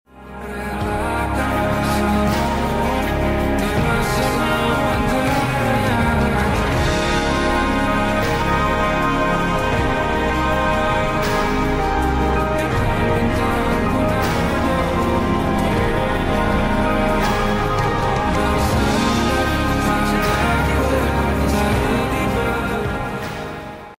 lagu horor sound effects free download